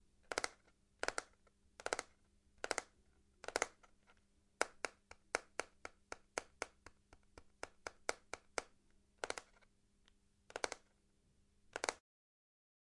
道具 " 手指敲击木头长环01
描述：手指在长凳上敲击。
标签： 手指攻 丝锥 弗利
声道立体声